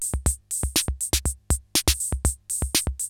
CR-68 LOOPS3 5.wav